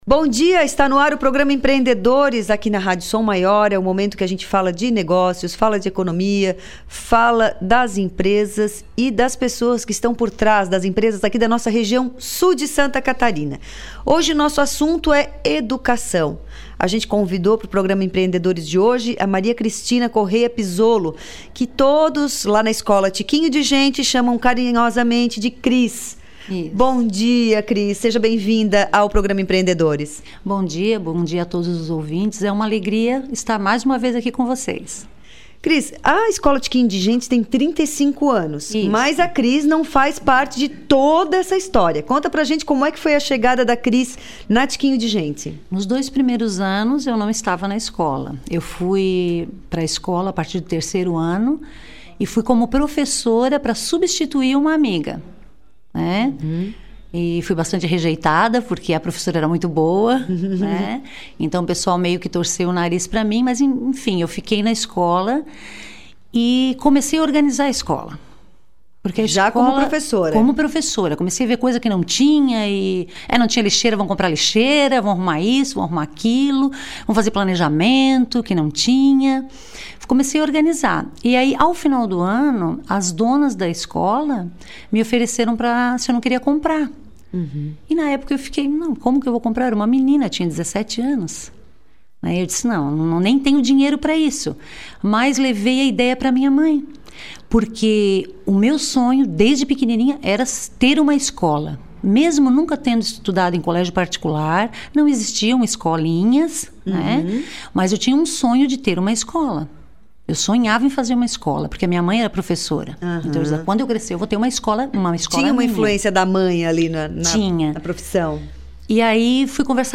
Entrevista
O Programa Empreendedores é veiculado originalmente na Rádio Som Maior.